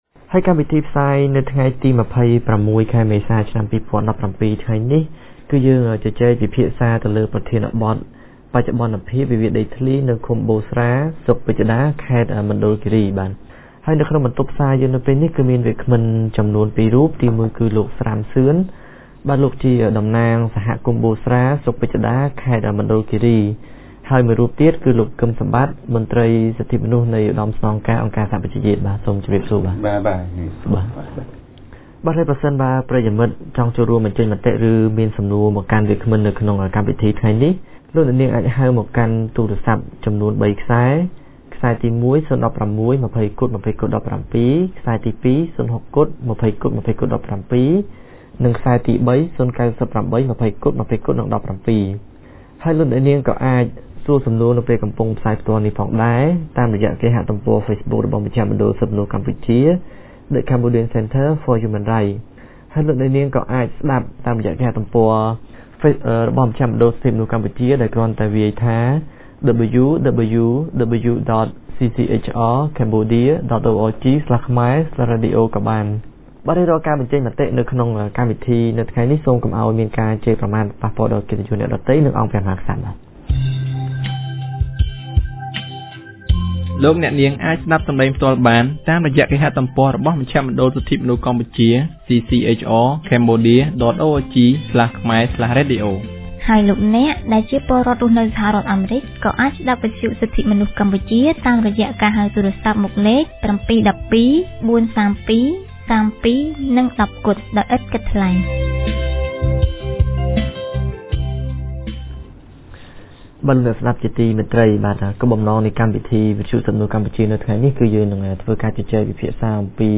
On Wednesday , April 26, 2017 CCHR’s radio program held a talk show on topic “ Update on the land conflict in Busra commune, Pichrada district, Mondulkiri province”.